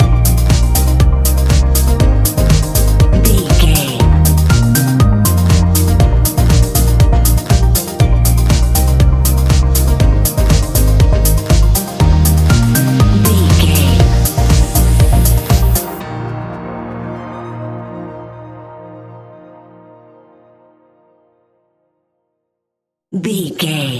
Ionian/Major
A♯
house
electro dance
synths
techno
trance